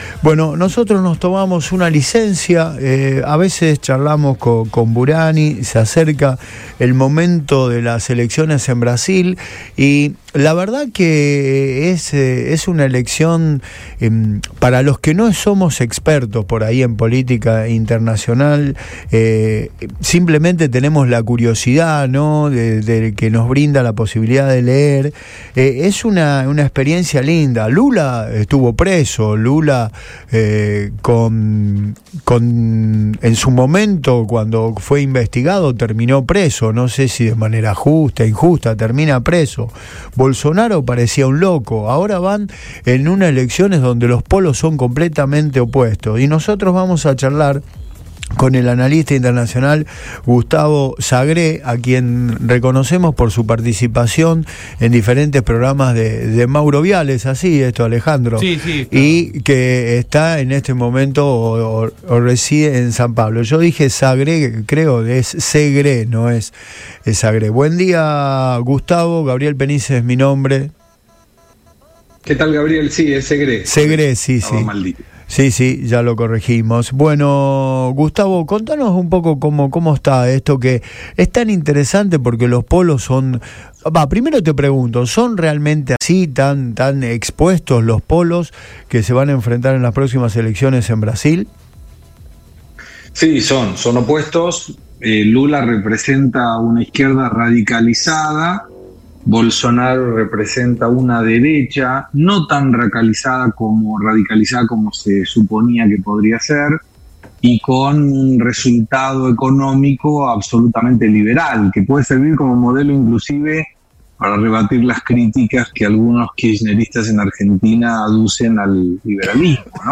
analista internacional, en diálogo con